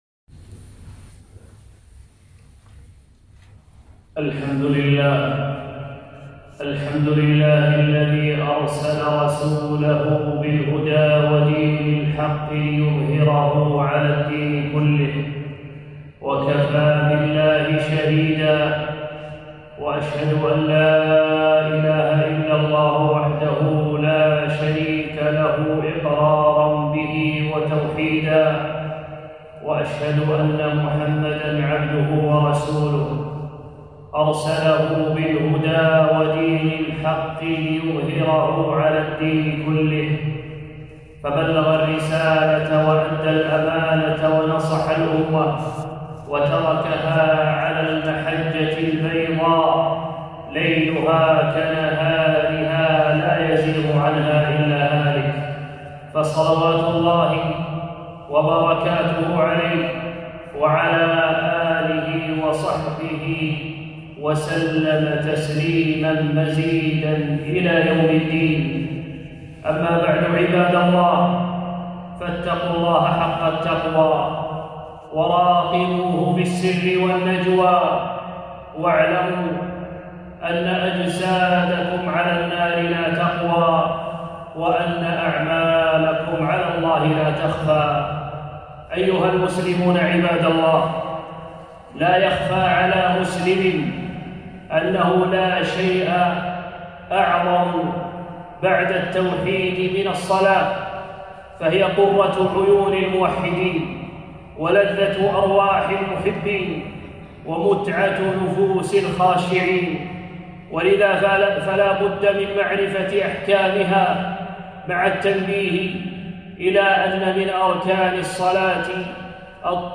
خطبة - صفة الصلاة